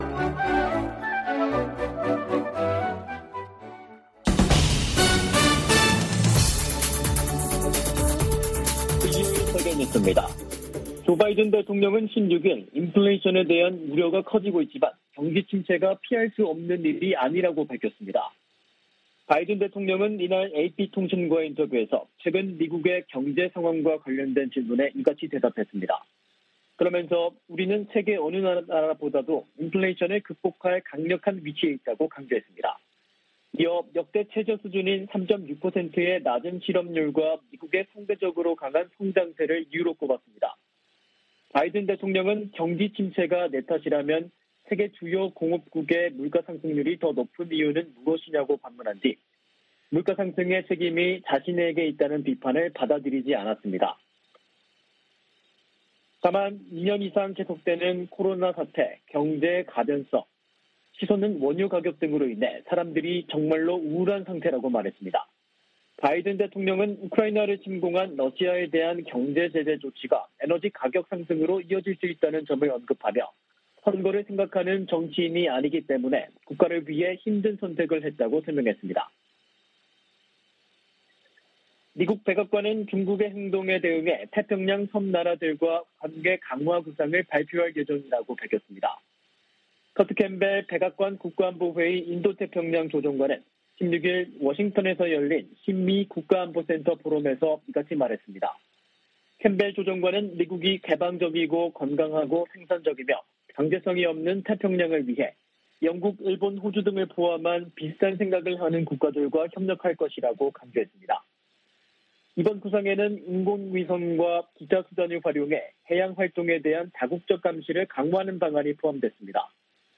VOA 한국어 간판 뉴스 프로그램 '뉴스 투데이', 2022년 6월 17일 3부 방송입니다. 미 국무부가 대화와 외교로 북한 핵 문제를 해결한다는 바이든 정부 원칙을 거듭 밝혔습니다. 북한의 7차 핵실험 가능성이 계속 제기되는 가운데 미국과 중국이 이 문제를 논의하고 있다고 백악관 고위 당국자가 밝혔습니다. 미국의 전문가들은 북한의 풍계리 핵실험장 4번 갱도 움직임은 폭발력이 다른 핵실험을 위한 것일 수도 있다고 분석했습니다.